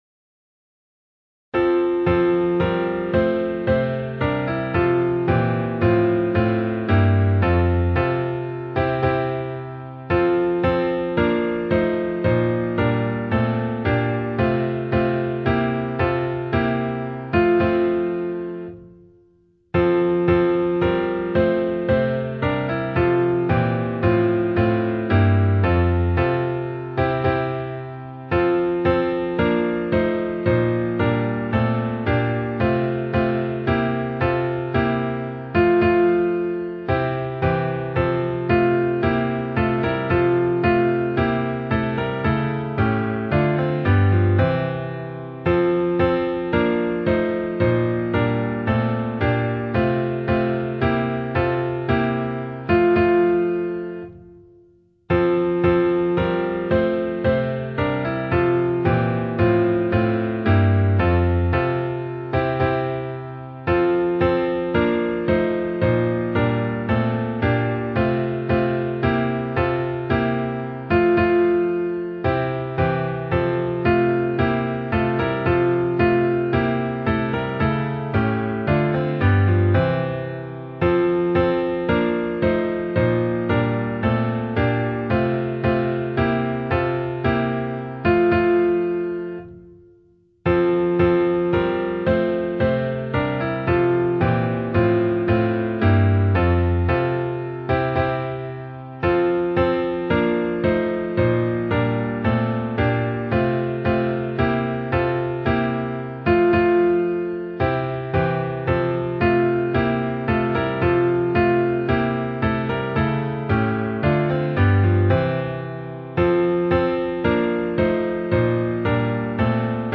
• Key: G